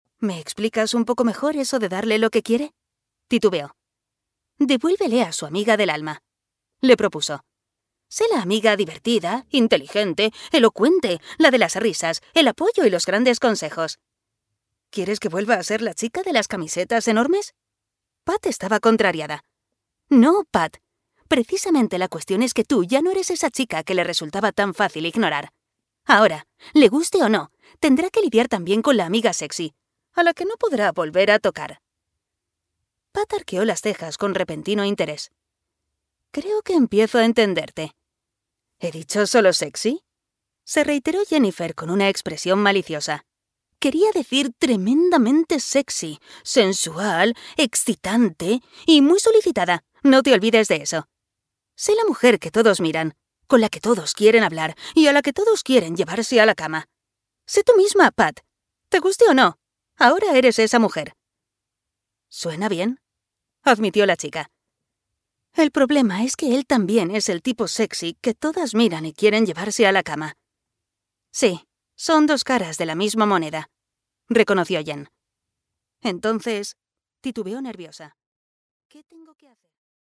Audiolibro Tentados por el Deseo (Tempted by Desire)